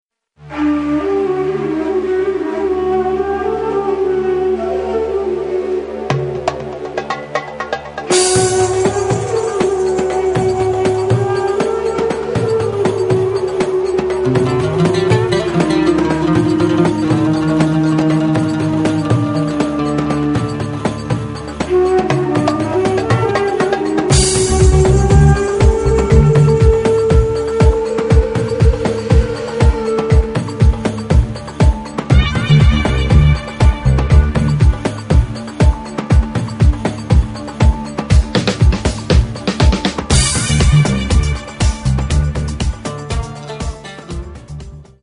アラブ〜マグレブ〜インドの伝統的音楽に、ダブやアフロビートをミックス。